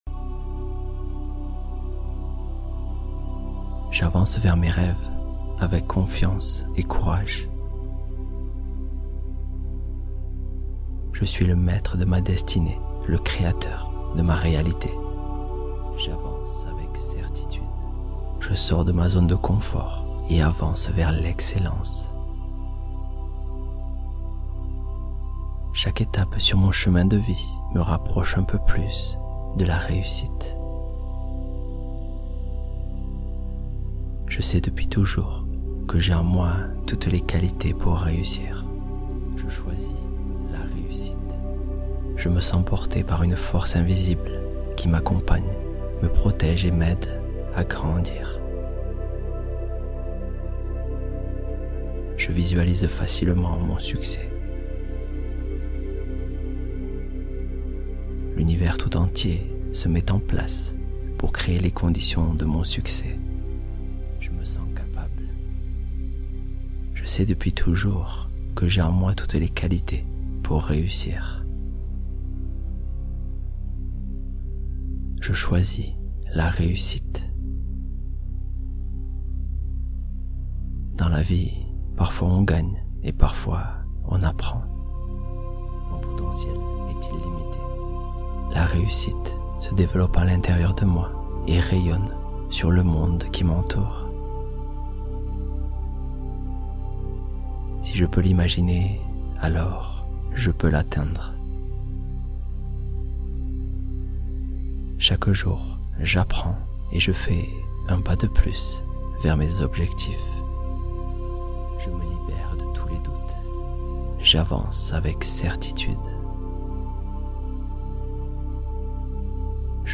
Attirez Le SUCCÈS Dans Votre Vie Automatiquement | Affirmations Loi De L'Attraction